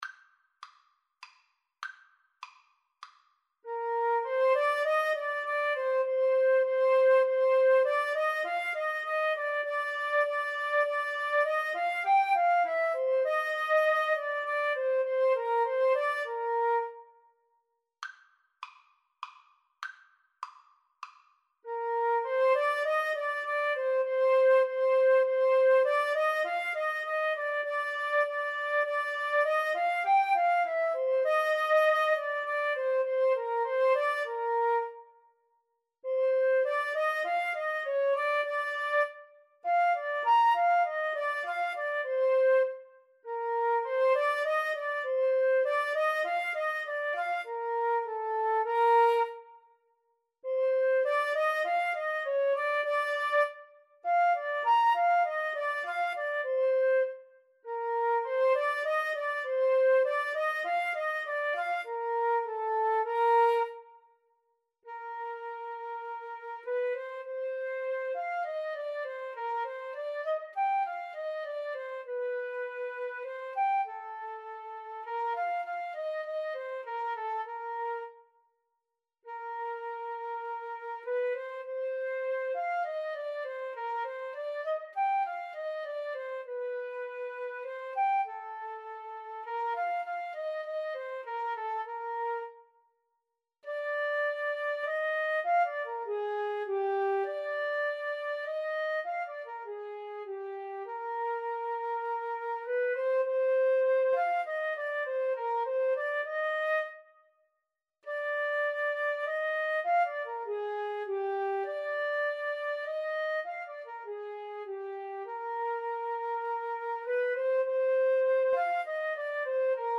3/4 (View more 3/4 Music)
Menuetto
Classical (View more Classical Flute-Clarinet Duet Music)